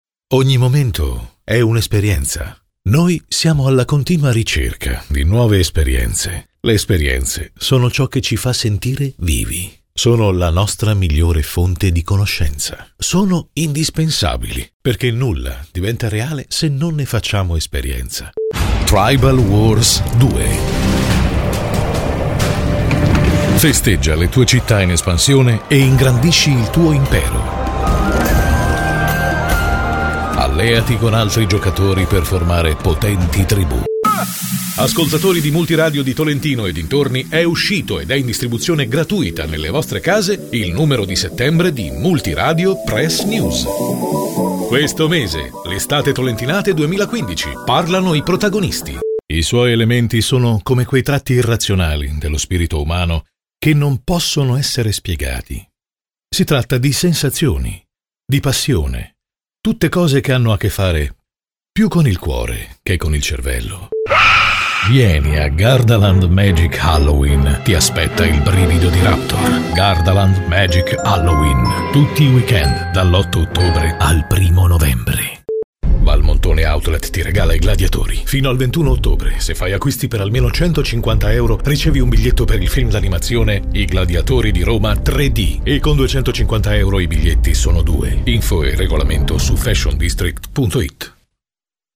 una voce maschile versatile: calda e profonda o dinamica e piena di energia.
Sprechprobe: Werbung (Muttersprache):
versatile Voice: warm and deep or dynamic and full of energy.